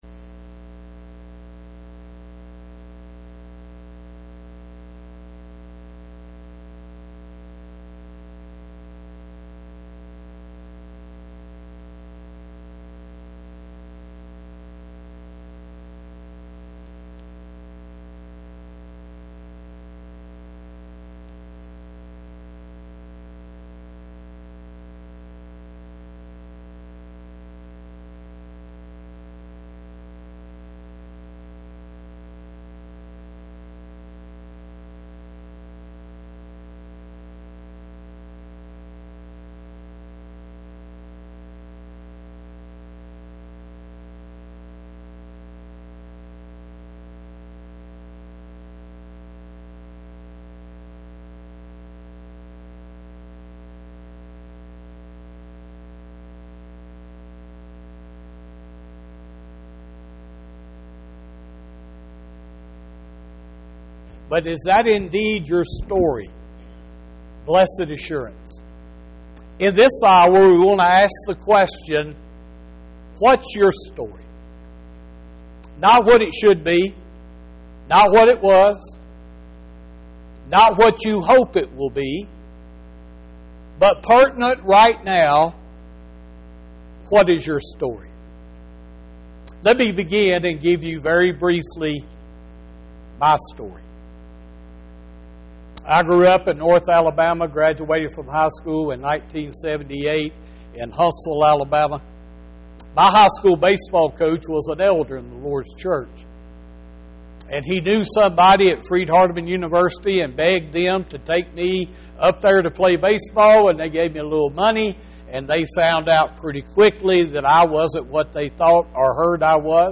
10-26-25 – What’s Your Story? – Gospel Meeting